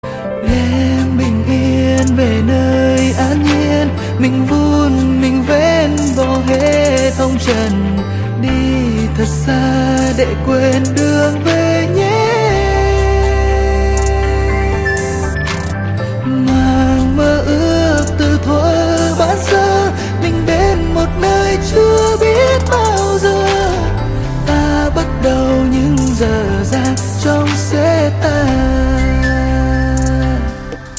Nhạc Chuông Nhạc Trẻ